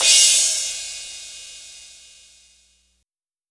• Crash G# Key 05.wav
Royality free crash sample tuned to the G# note. Loudest frequency: 6305Hz
crash-g-sharp-key-05-Sjb.wav